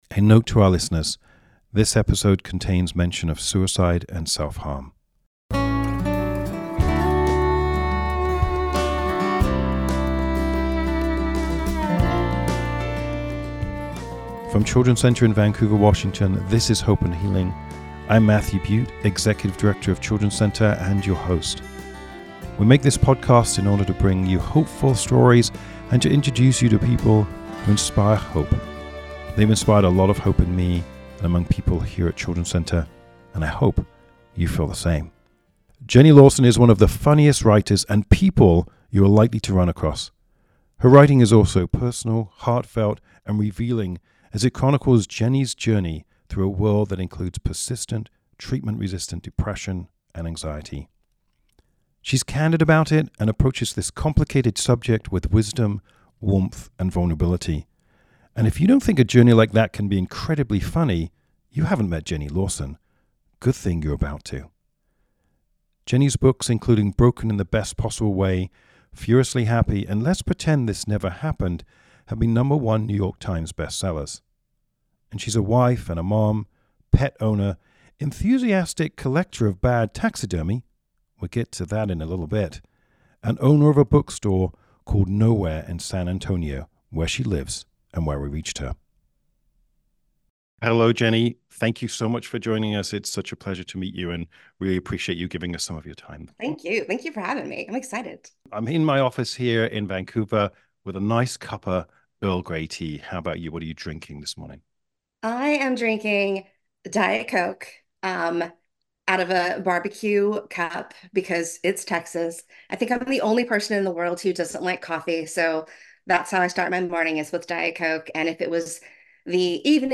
In this candid and, at times, very funny interview, Jenny talks about the healing power of being open about her longtime struggles with depression and anxiety.